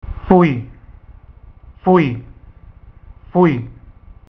pronunciation (from Bratislava, Slovakia)